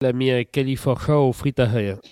Mots Clé arbre(s), arboriculture ; Localisation Saint-Jean-de-Monts
Catégorie Locution